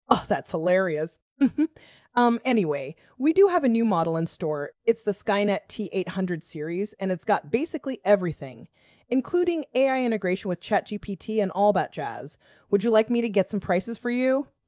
text-to-speech voice-cloning
The fastest open source TTS model without sacrificing quality.